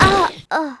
女孩被杀zth070511.wav
通用动作/01人物/04人的声音/死亡/女孩被杀zth070511.wav
• 声道 單聲道 (1ch)